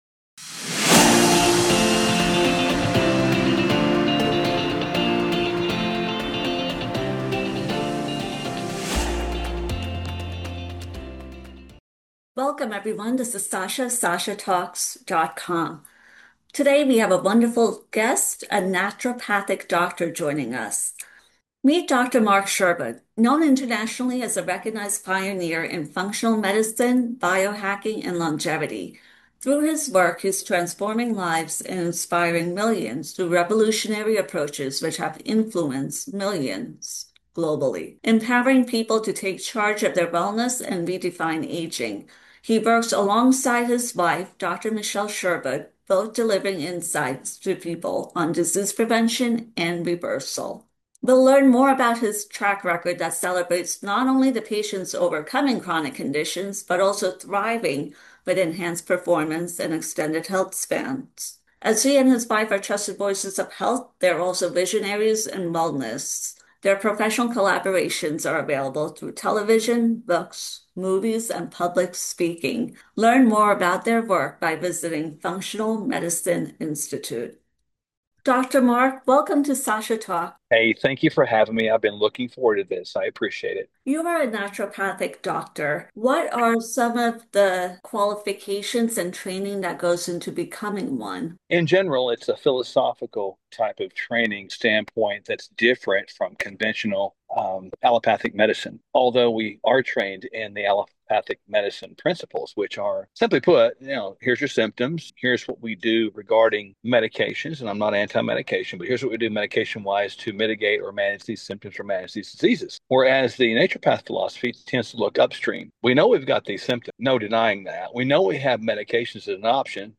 Naturopathic Doctor